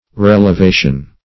Meaning of relevation. relevation synonyms, pronunciation, spelling and more from Free Dictionary.